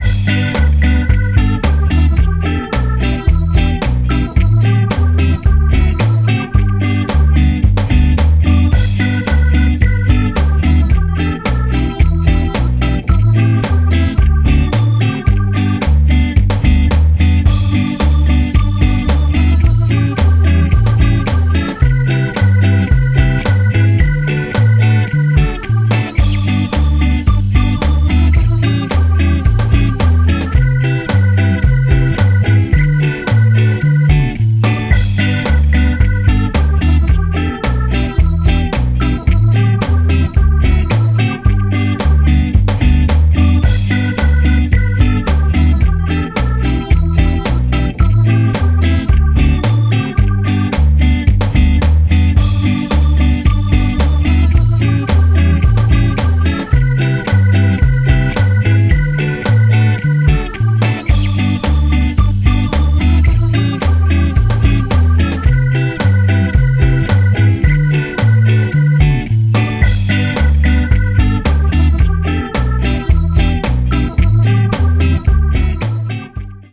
(1:16) Some funny and sunny ska/rocksteady story in LR